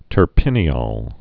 (tər-pĭnē-ôl, -ōl, -ŏl)